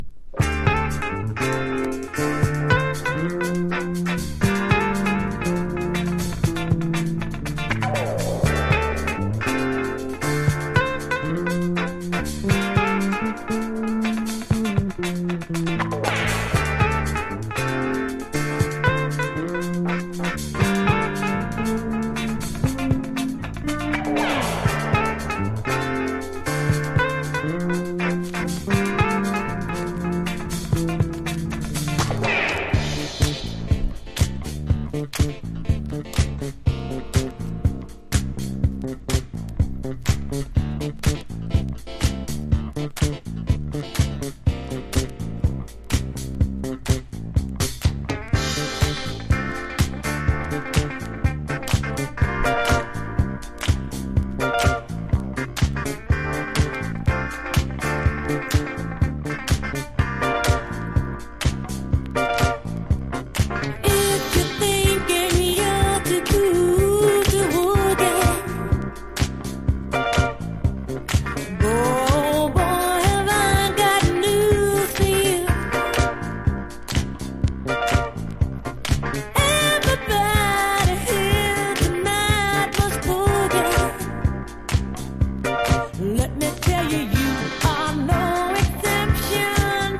FREE SOUL～DANCE CLASSICS!!
FUNK / DEEP FUNK# DISCO